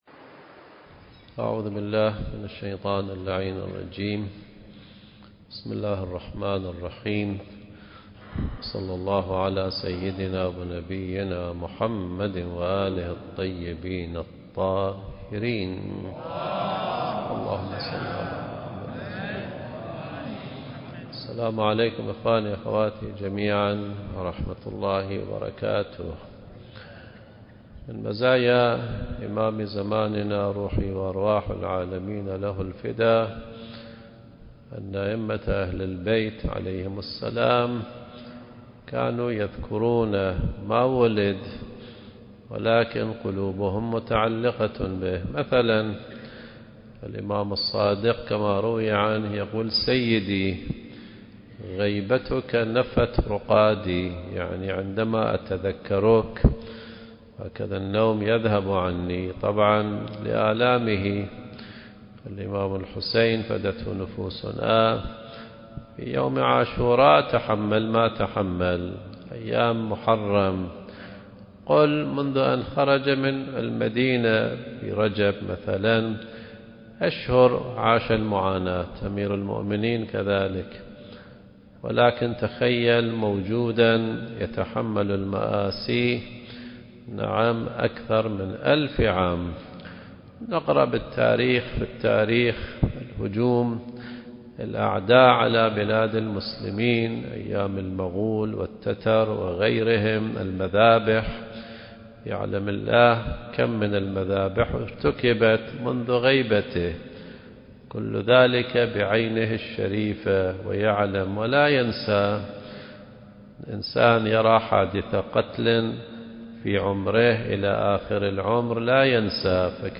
المكان: مسجد الصديقة فاطمة الزهراء (عليها السلام)/ الكويت التاريخ: 2024